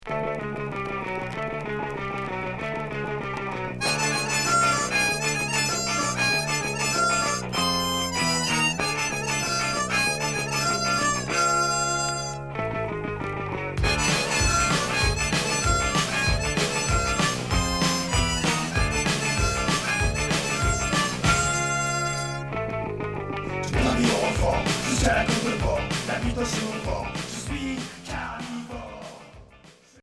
Punk rock Troisième 45t retour à l'accueil